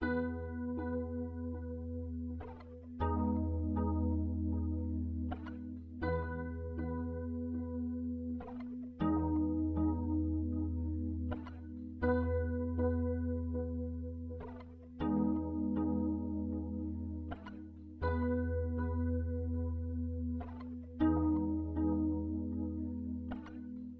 loopstation » guitar loop
描述：hazy guitar loop
标签： loop fx airy guitar
声道立体声